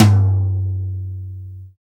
Index of /90_sSampleCDs/Roland - Rhythm Section/KIT_Drum Kits 7/KIT_Loose Kit
TOM SHARPT06.wav